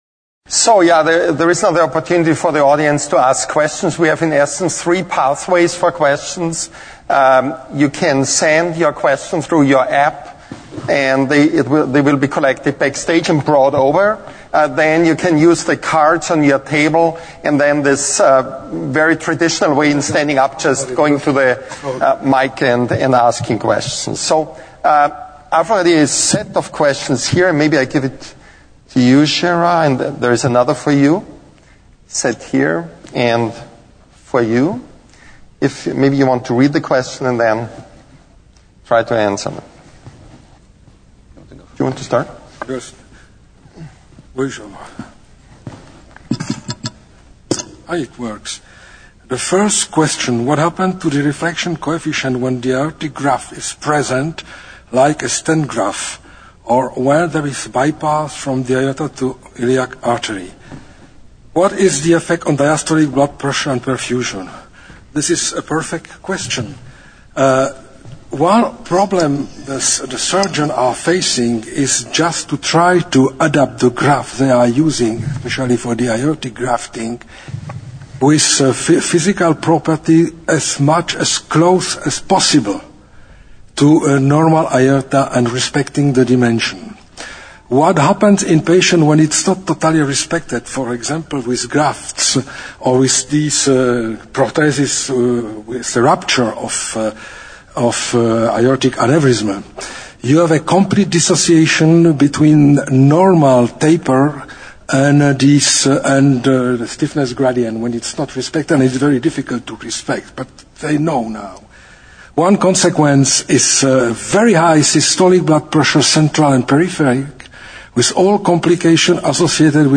MD Discussions Drs.